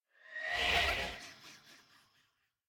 sounds / mob / vex / idle3.ogg